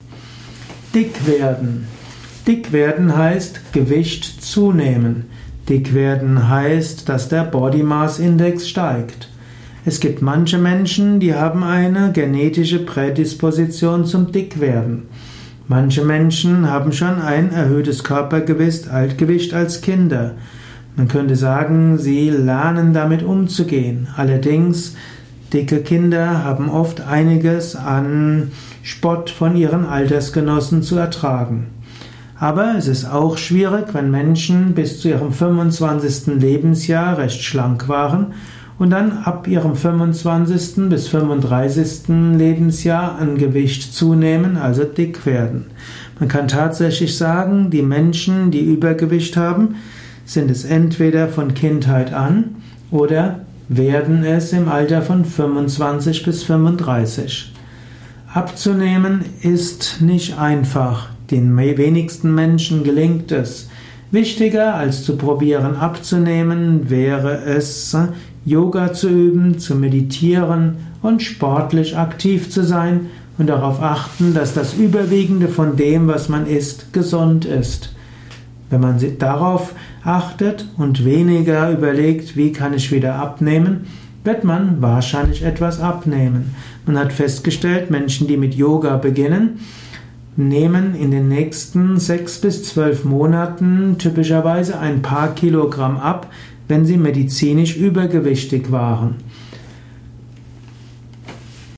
Ein Kurzvortrag über das Dick werden